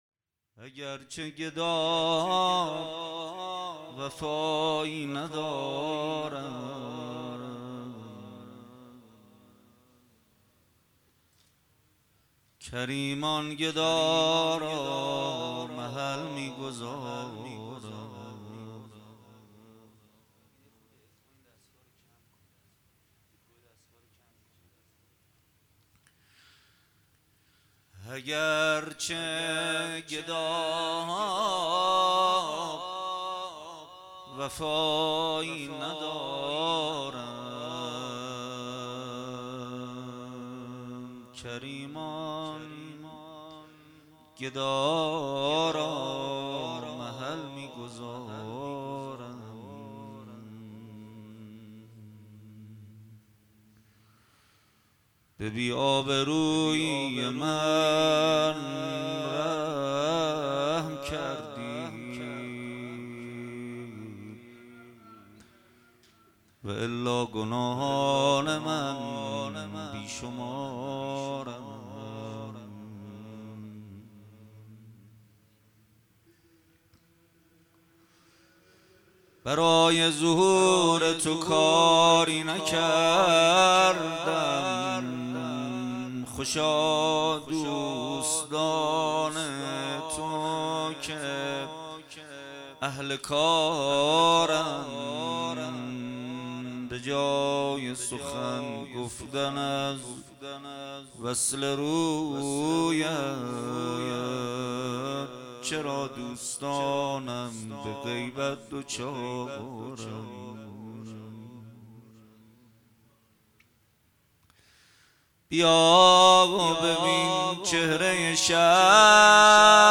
مداحی نوحه پرسوز